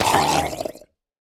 Minecraft Version Minecraft Version 1.21.5 Latest Release | Latest Snapshot 1.21.5 / assets / minecraft / sounds / mob / drowned / death1.ogg Compare With Compare With Latest Release | Latest Snapshot
death1.ogg